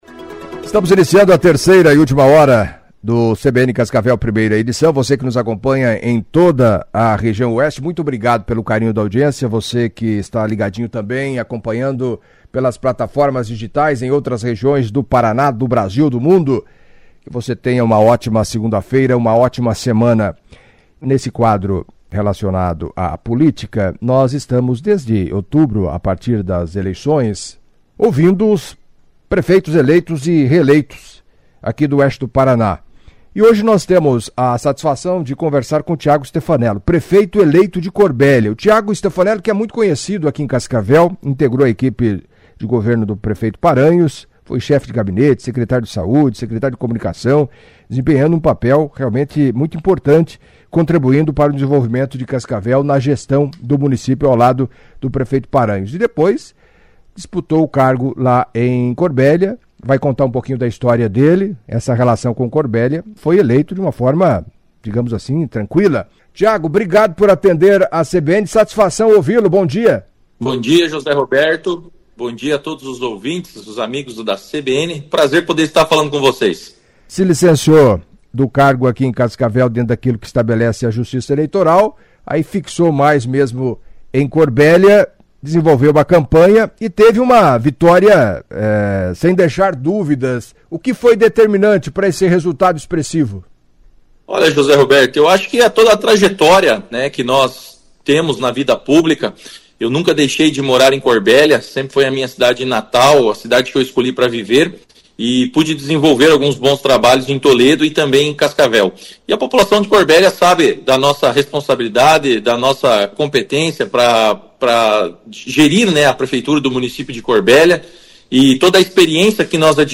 Em entrevista à CBN Cascavel nesta segunda-feira (09), na série de entrevistas com prefeitos eleitos e reeleitos da região Oeste do Paraná, Thiago Stefanello, do PP, vitorioso nas urnas em Corbélia com 7.196 votos, 66,91% dos votos válidos, detalhou como pretende conduzir os destinos do município pelos próximos quatro anos.